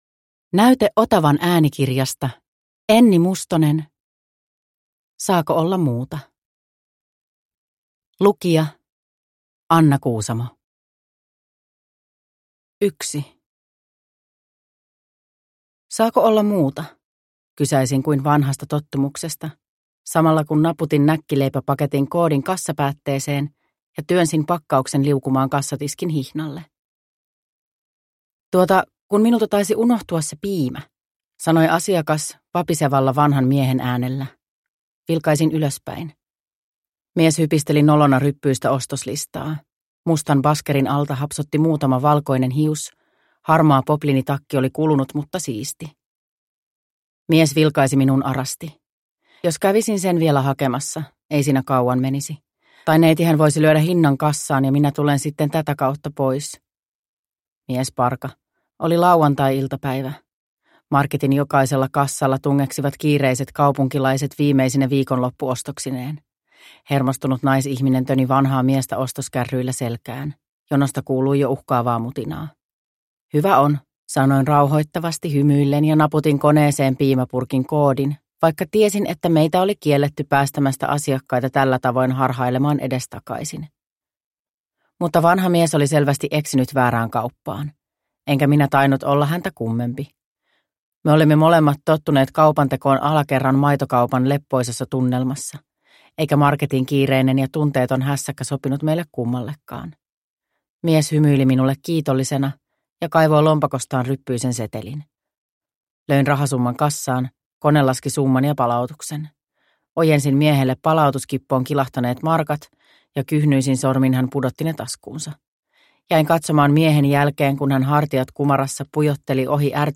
Saako olla muuta? – Ljudbok – Laddas ner
Produkttyp: Digitala böcker